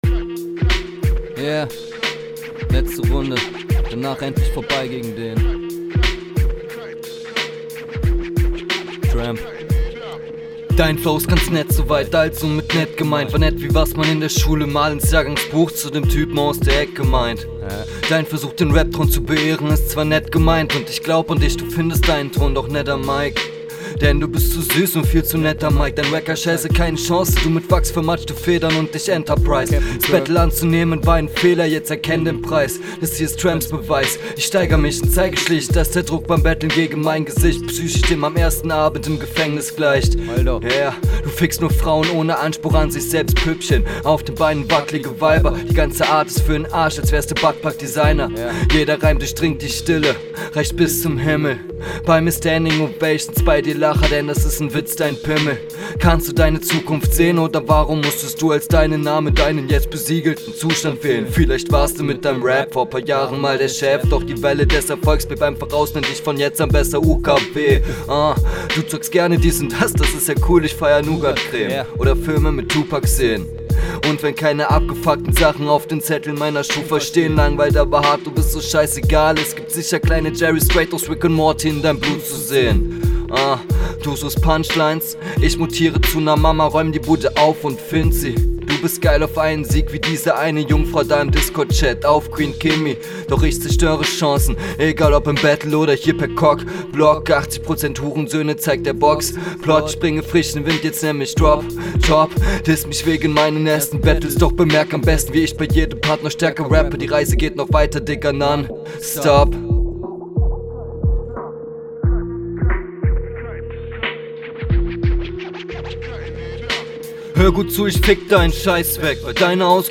Geiler beat, roboter kommt gleich wieder rein und Bruder, du rappst aber wieder so langweilig …
Finde auf den "aggressiveren und schnelleren" beats kommst du cooler!